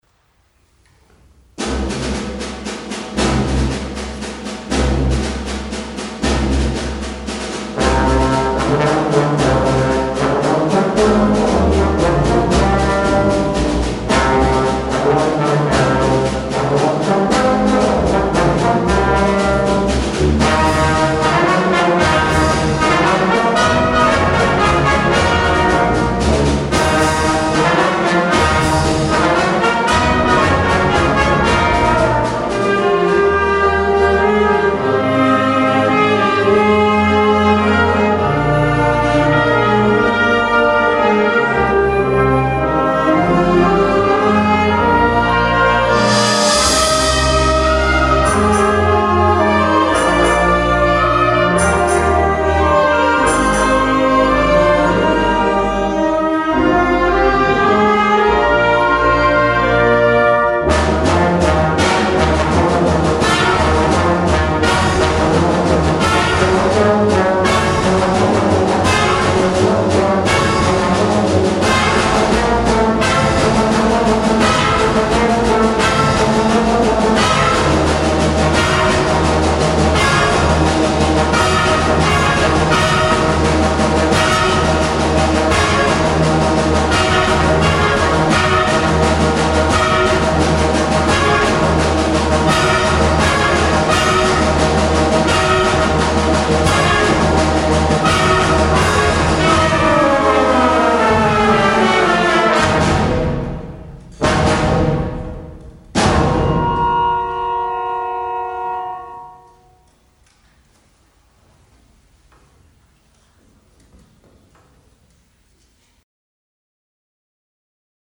Jahreskonzert 2019